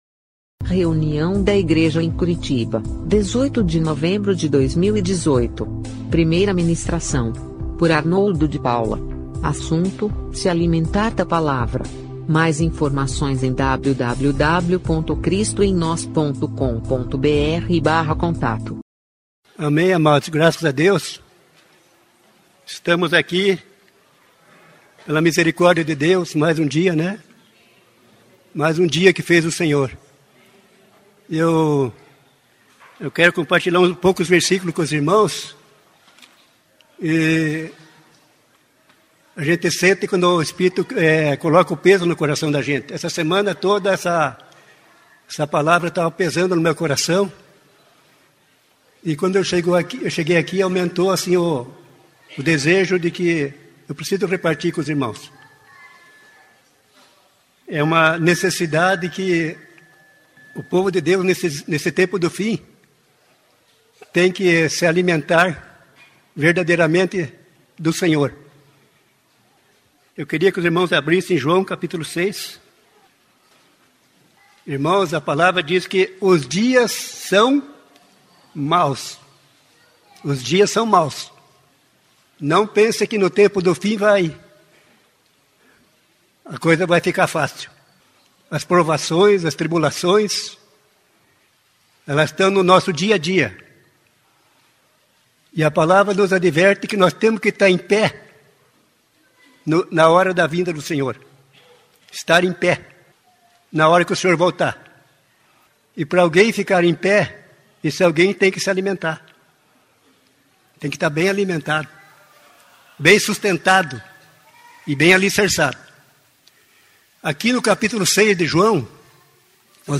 da reunião da igreja em Curitiba no dia 18/11/2018.